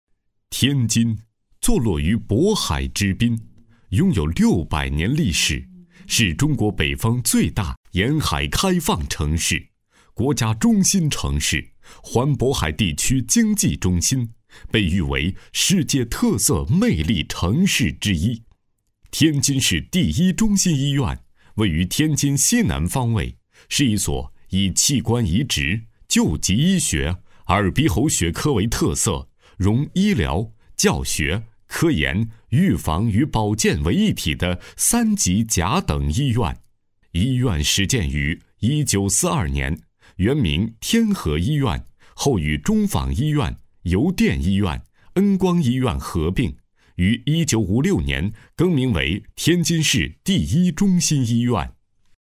激情力度 医疗专题
电台男播，品质男声。